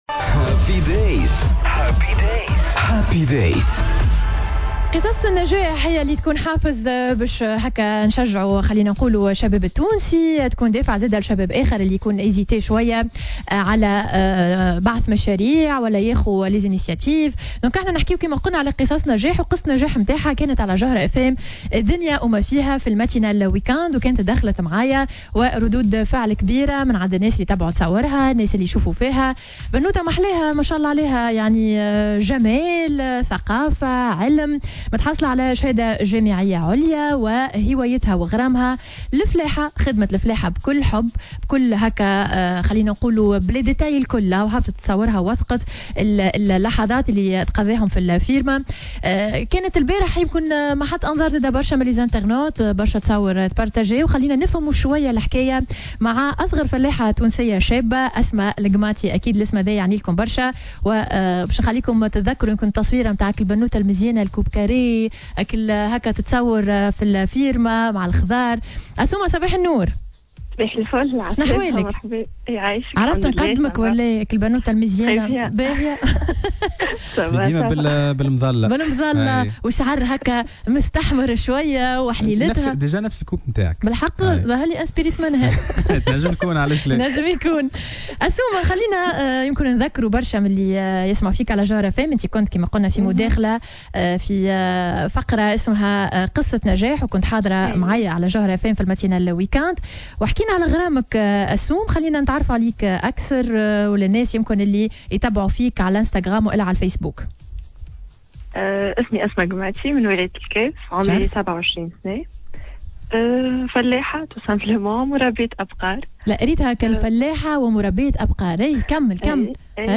خلال مداخلة لها في برنامج "هابي دايز" على موجات الجوهرة أف أم